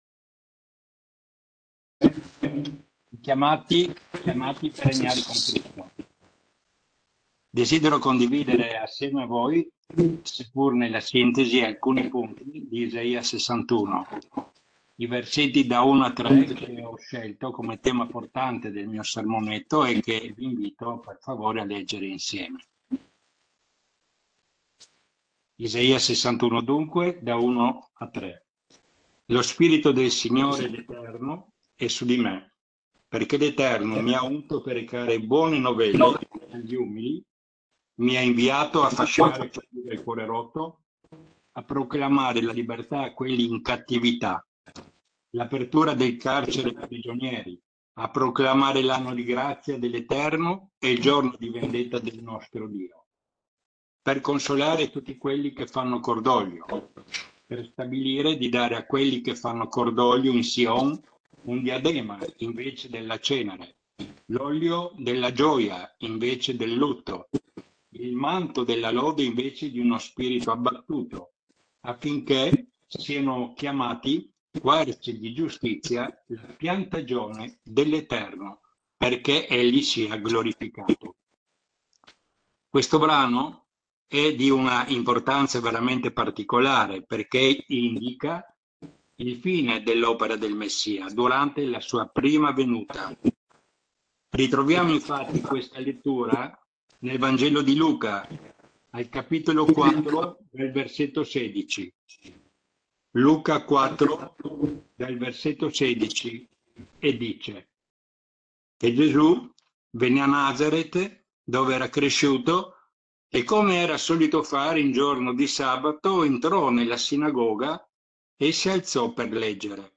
Chiamati e premiati in Cristo – Sermonetto